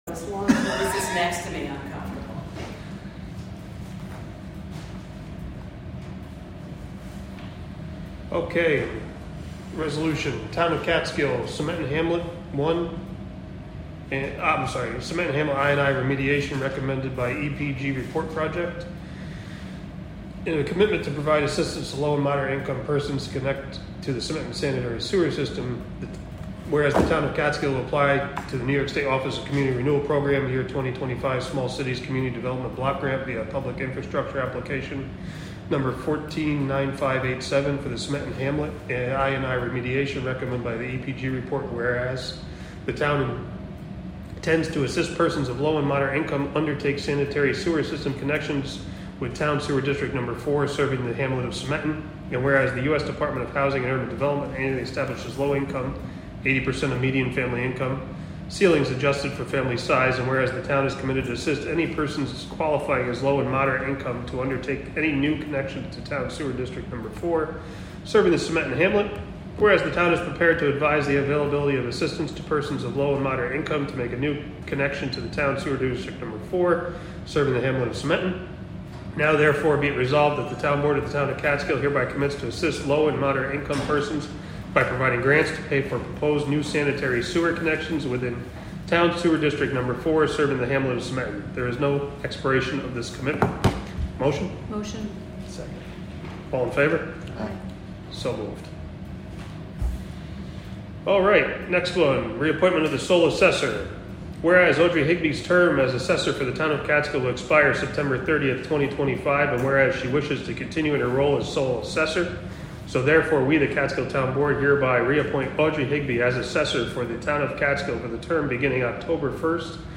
Live from the Town of Catskill: July 1, 2025 Catskill Town Board Meeting (Audio)